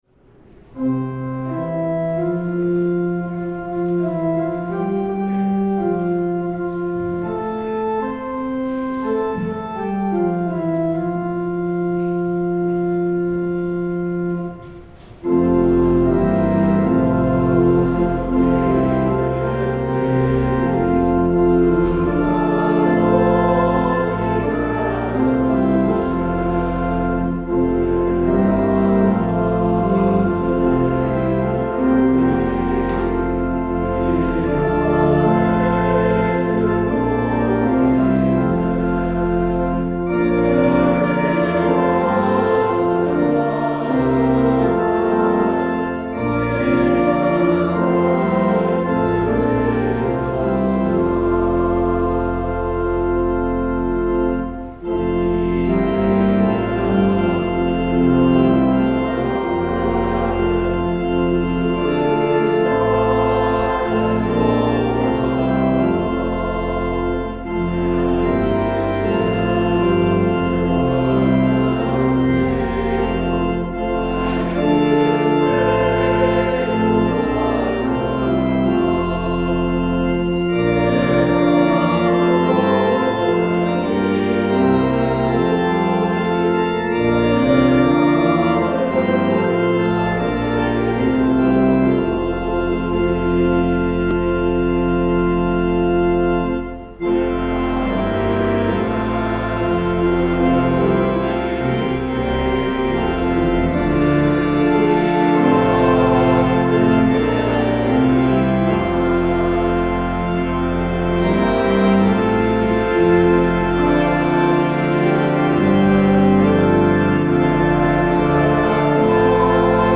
Sermons | St John the Evangelist
The Lord’s Prayer (sung)